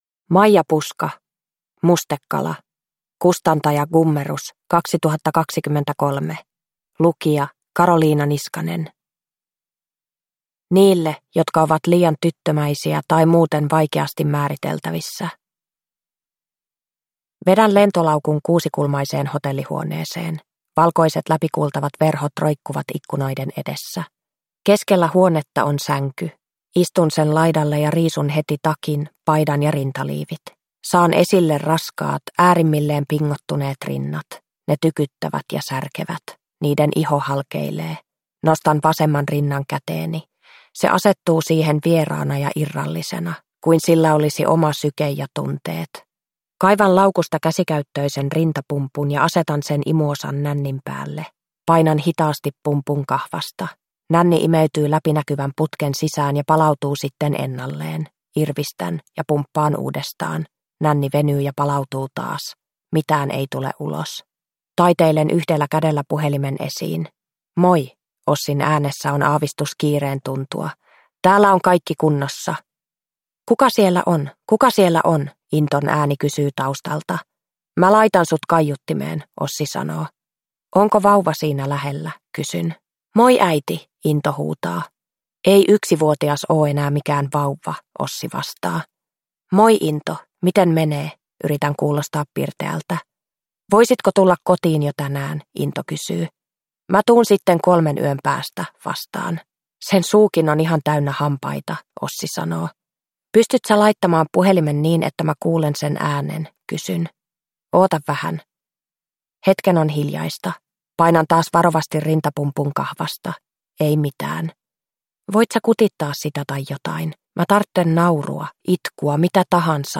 Mustekala – Ljudbok – Laddas ner